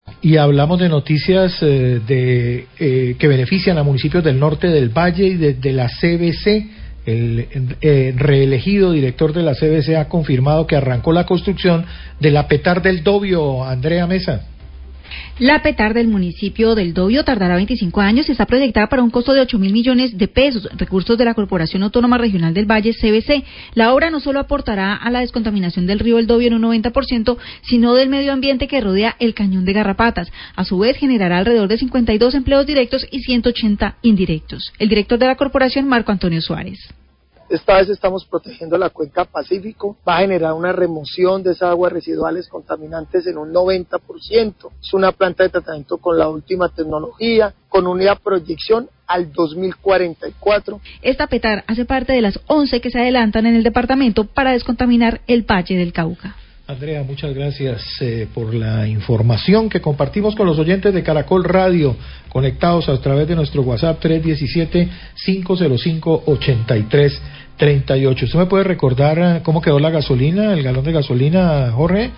La CVC inició las obras de construcción de la PTar del Dovio que reducirá en un 90% la contaminación de las aguas vertidas al Río El Dovio y el medio ambiente que rodea al Cañón del Garrapata. Declaraciones del recién re-elegido director general de la CVC, Marco Antonio Suárez.